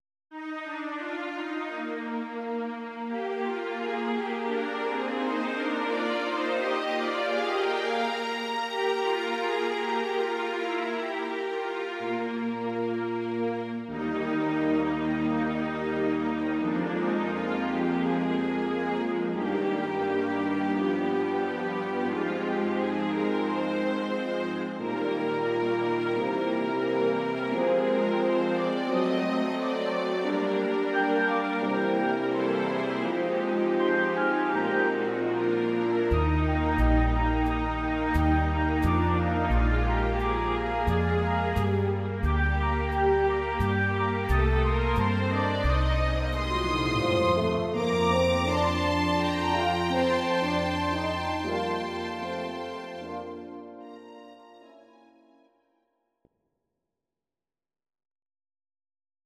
Please note: no vocals and no karaoke included.
Your-Mix: Jazz/Big Band (731)